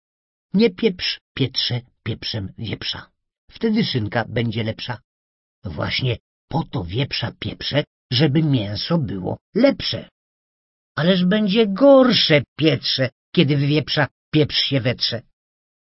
А вот послушайте самое начало того стихотворения, и вы всё поймёте сами (читает киноактёр Мачей Дамецкий):
Конечно же, это настоящая скороговорка, да ещё и специально написанная так, чтобы получилось посмешнее.